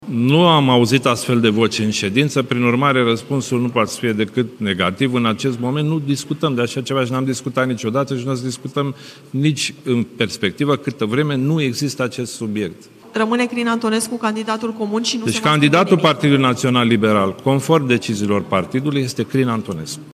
Președintele interimar al PNL, Cătălin Predoiu: „Candidatul Partidului Național Liberal, conform deciziilor partidului, este Crin Antonescu”